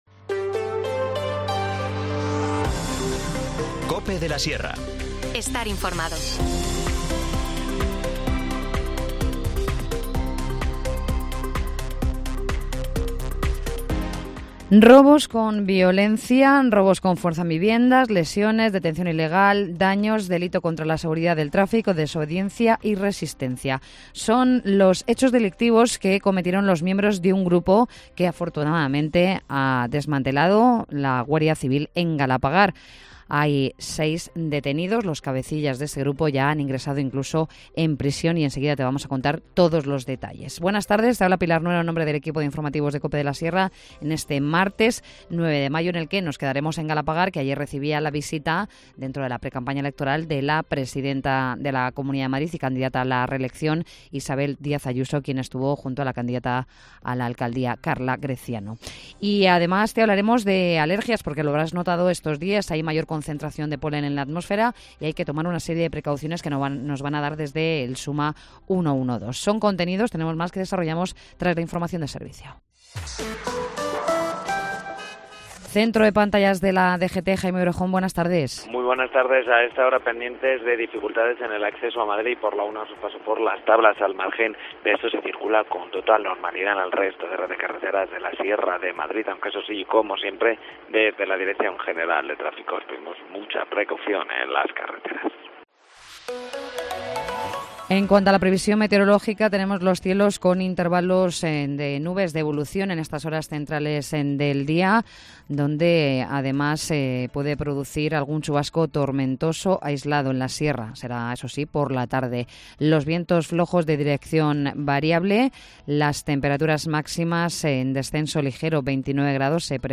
Informativo Mediodía 9 mayo